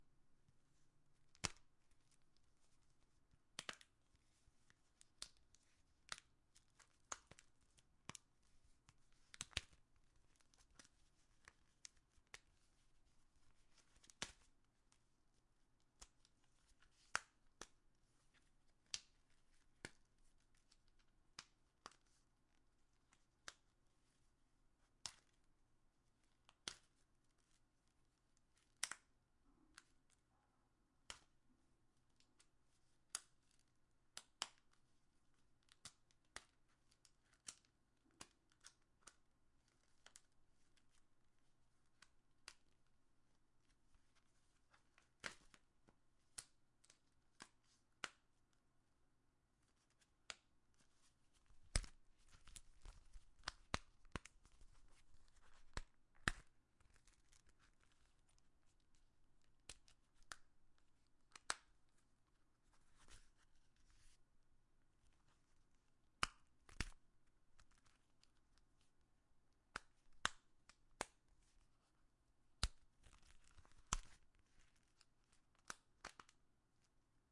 На этой странице собраны разнообразные звуки застежек и фермуаров от женских кошельков, сумок и других аксессуаров.
Звук кошелька при открытии или закрытии